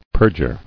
[per·jure]